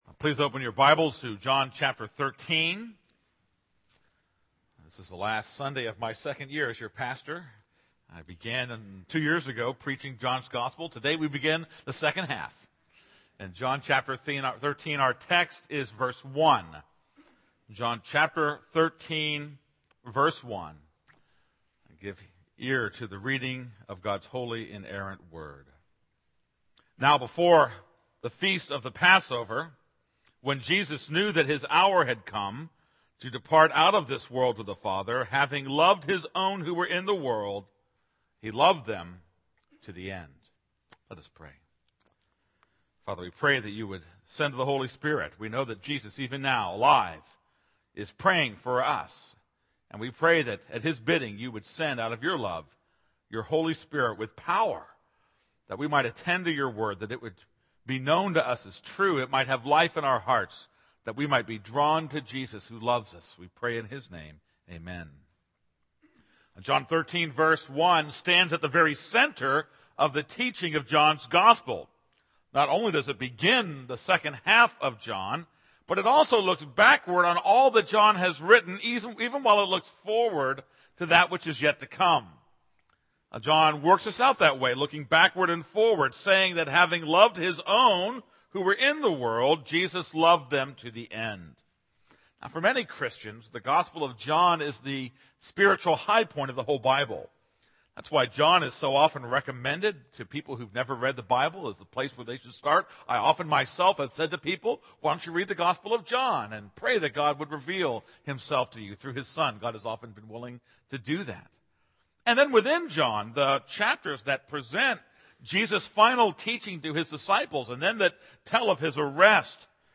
This is a sermon on John 13:1.